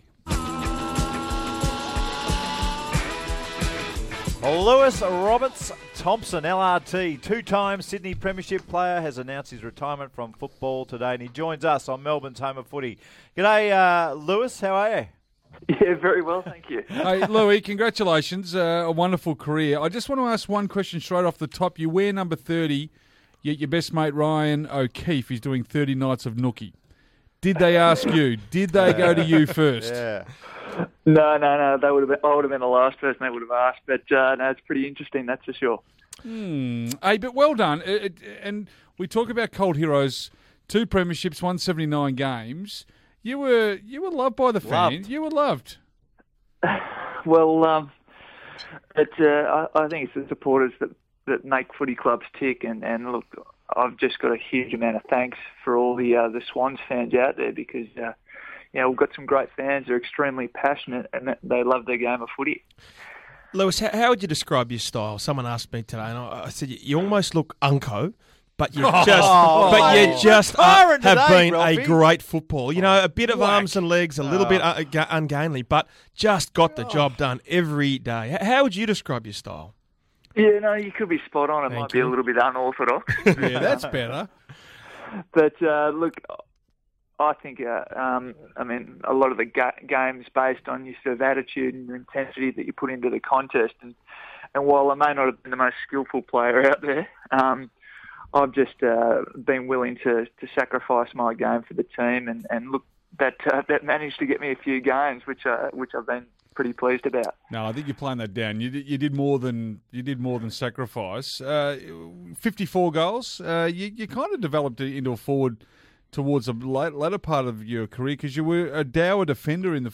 Retiring Sydney Swan Lewis Roberts-Thomson appeared on 1116SEN's Run Home program on Thursday August 14, 2014